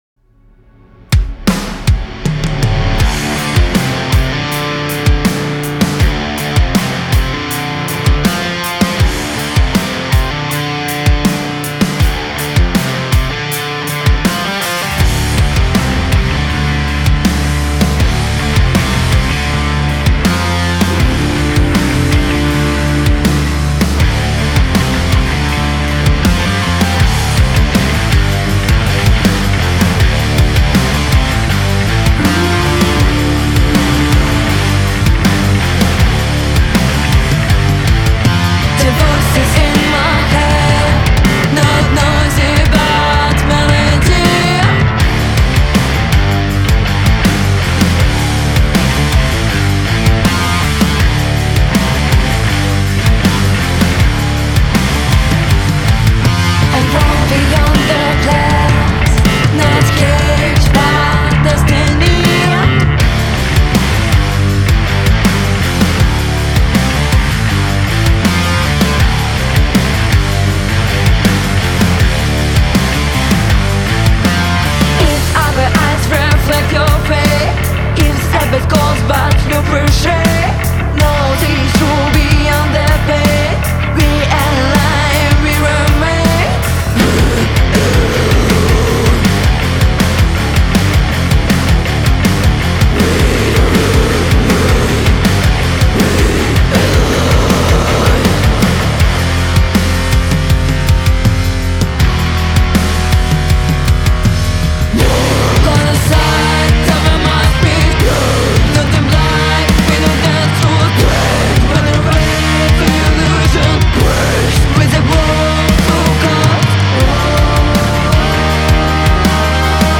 Стиль: prog metal stoner